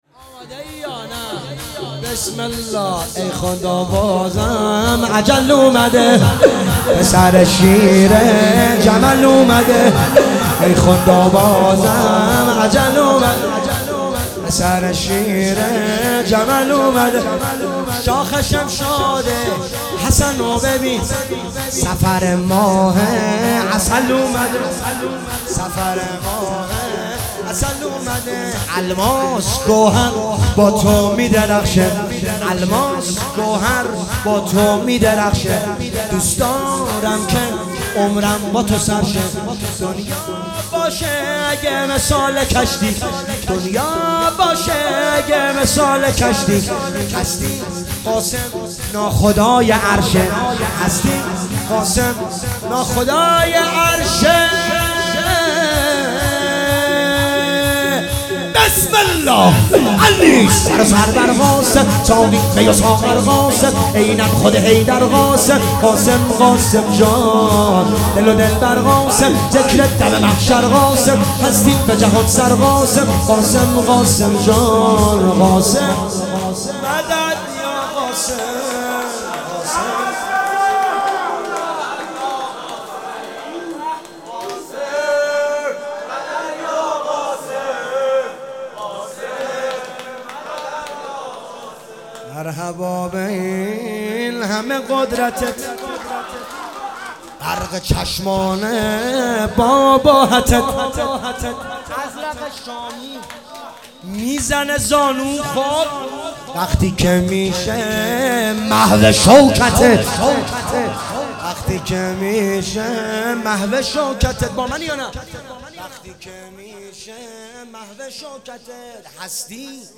شور - ای خدا بازم اجل آمده